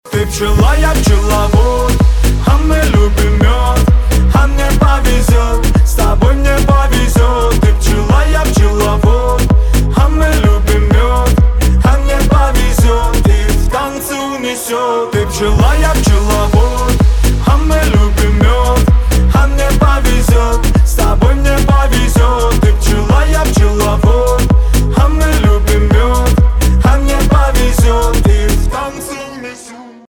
• Качество: 320, Stereo
поп
зажигательные
веселые
заводные